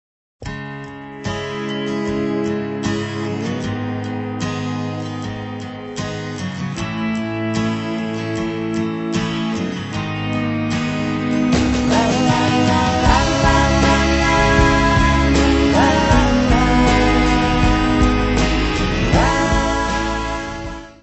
voz, guitarras acústicas
baixo, coros
bateria, pandeireta, shaker
guitarras eléctricas
hammond, piano, pandeireta.
trompete
trombone
: stereo; 12 cm
Área:  Pop / Rock